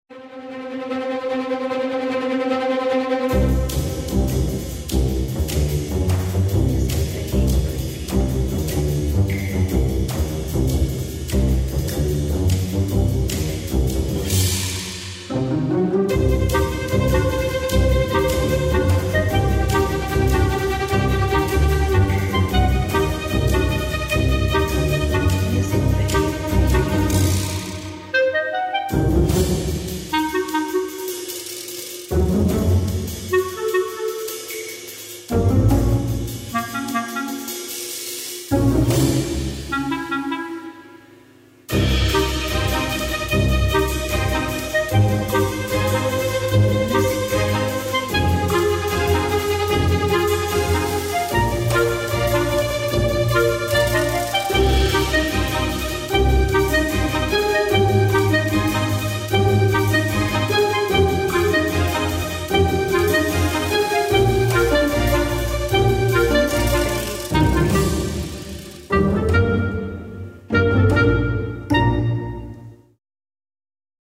Quirky music for video.
Orchestral background music
Tempo (BPM): 118